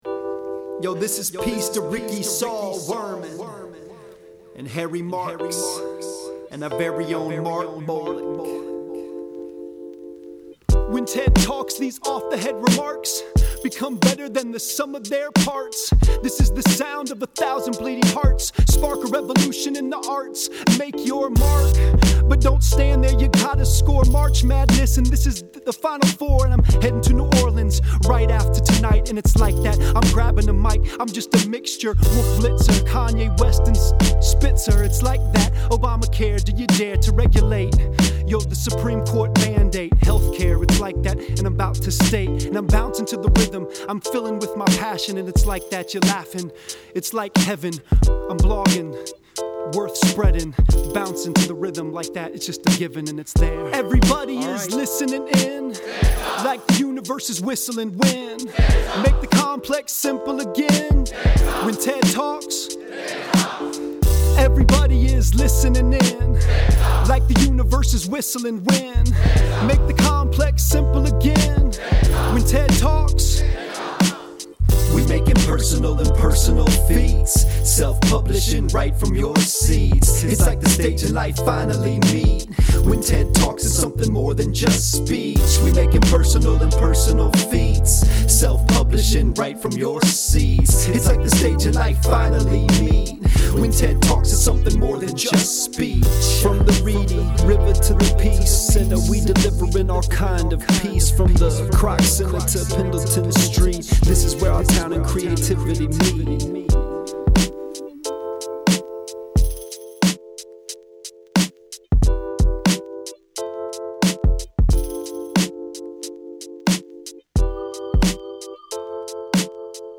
TEDxGreenville gets busy on a live track.
Written and recorded by the TEDxGreenville community live on stage.